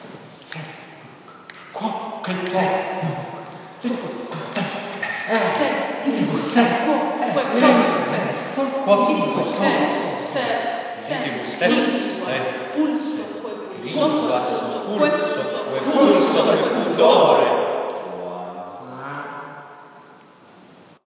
“Kikker Fuga”: Tekstuele Improvisatie gebaseerd op de Metamorfosen van Ovidius
Live opname